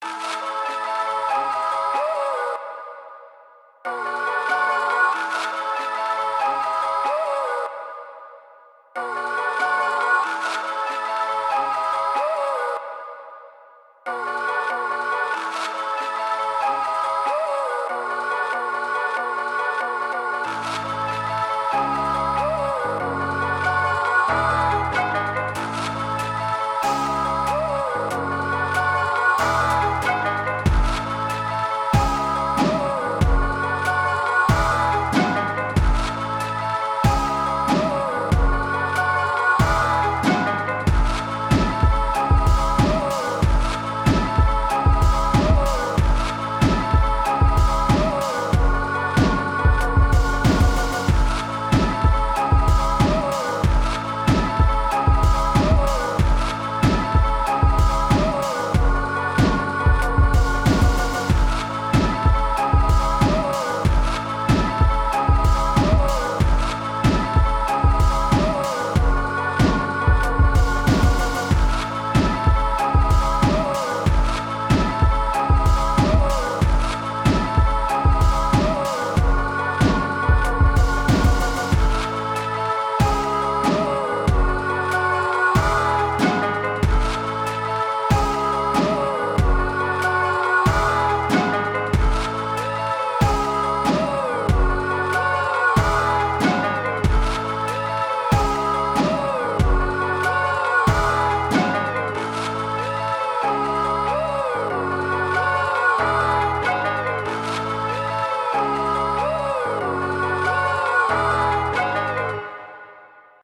doo_wop_ghosts.ogg